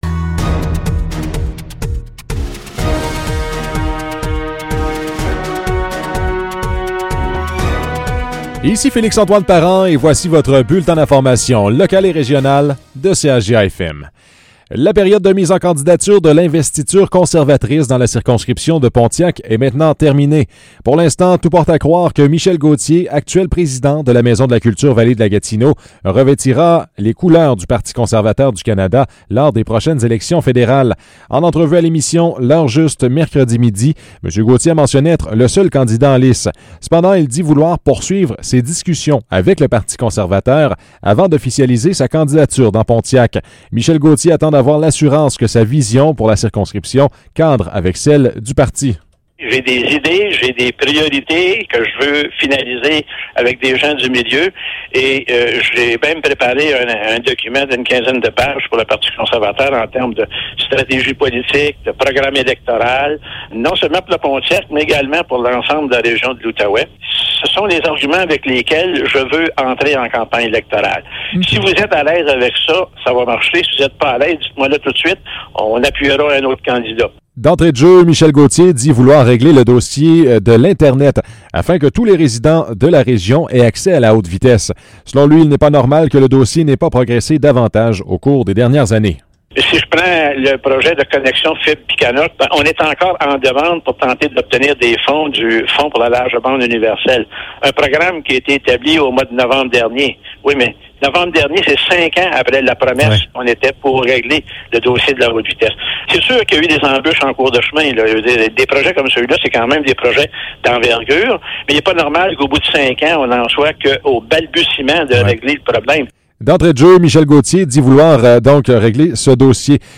Nouvelles locales - 28 janvier 2021 - 12 h